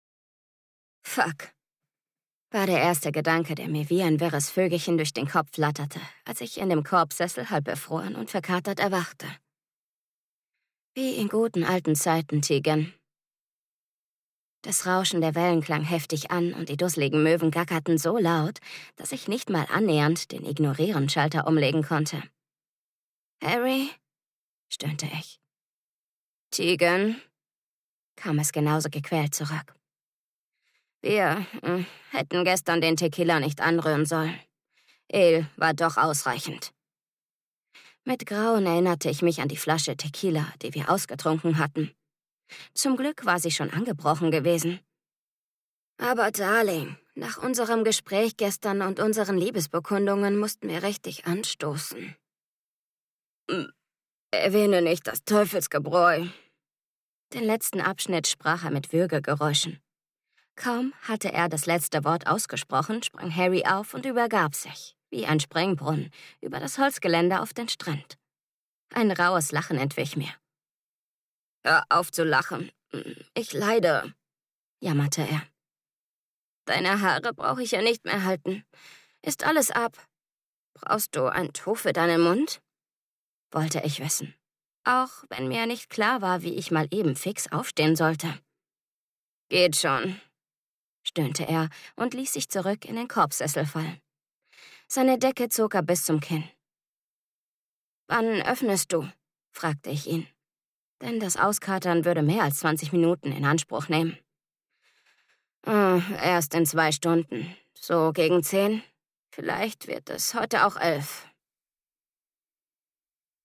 Stimmfarbe: hell, warm, wandlungsfähig